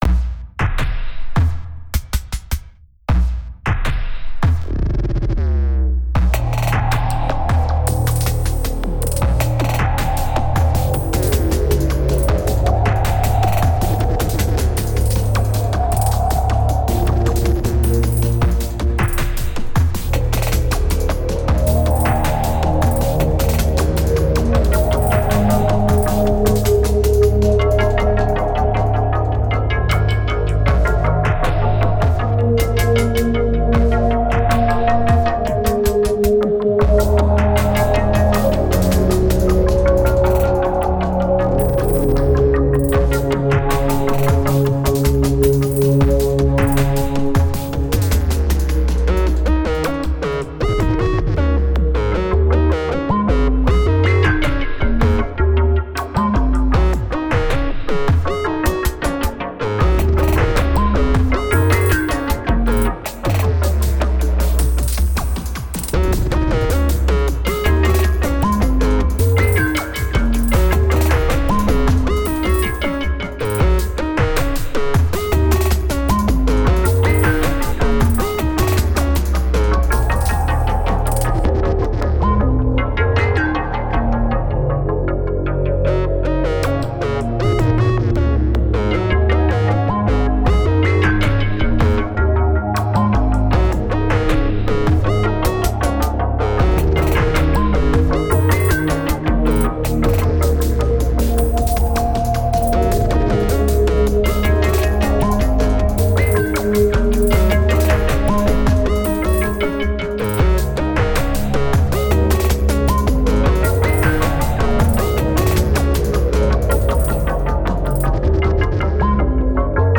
Genre: IDM.